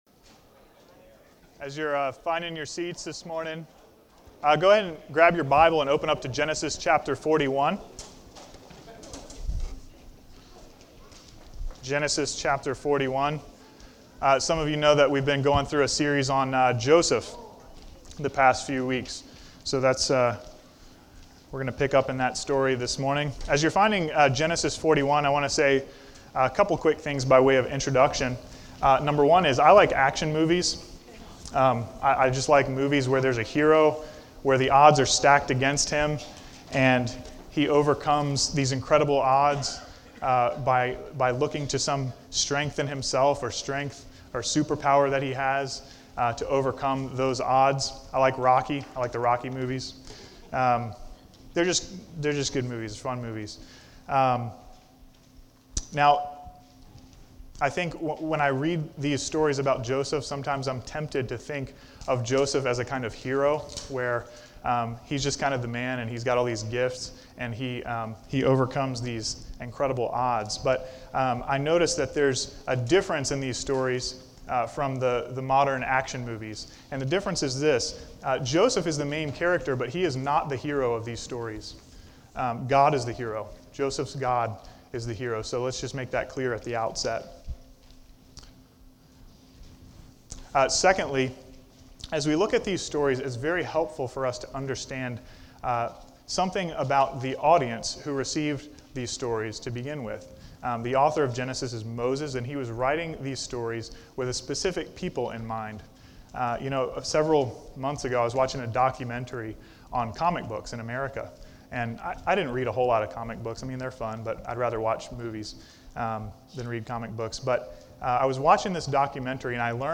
A message from the series "Heaven Help The Home."